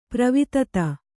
♪ pravitata